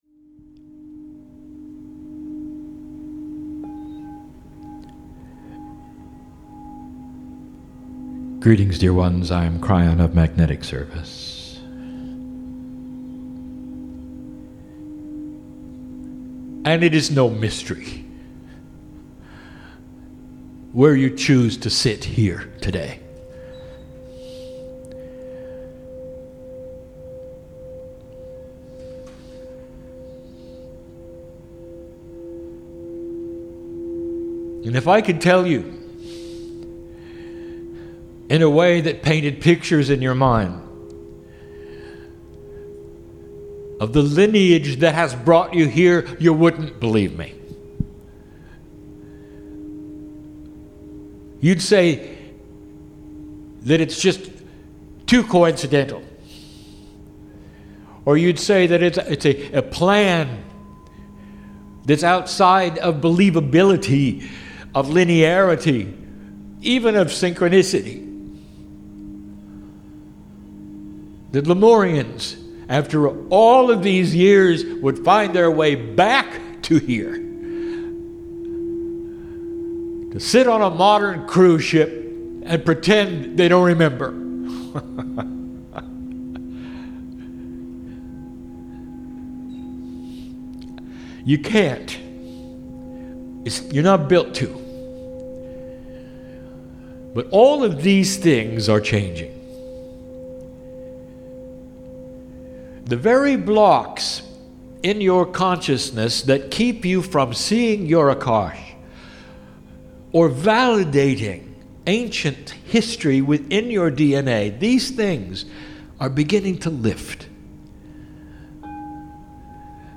Sunday Aug 12 "mini channelling"
Sunday Mini channelling - FILE IS NAMED: "Hawaii 2012 Sunday mini.mp3"